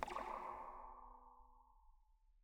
zap3_v1.wav